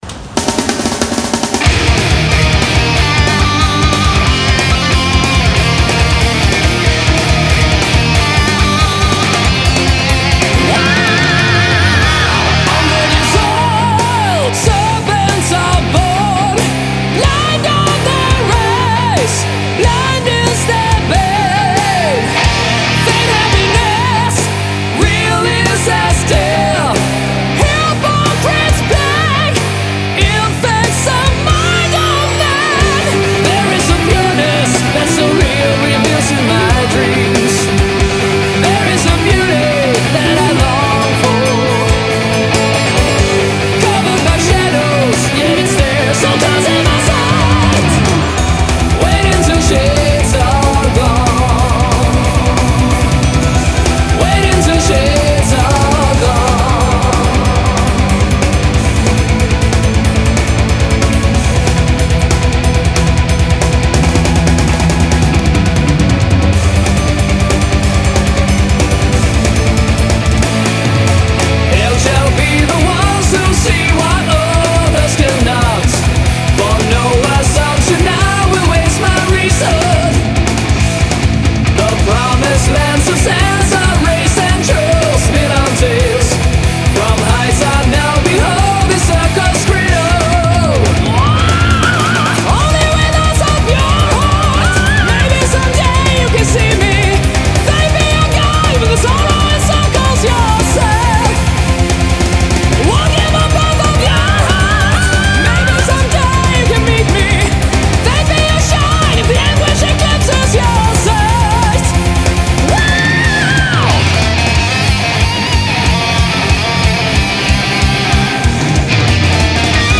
BPM185
Audio QualityPerfect (High Quality)
Powerful metal.